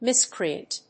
音節mis・cre・ant 発音記号・読み方
/mískriənt(米国英語), ˈmɪs.kri.ənt(英国英語)/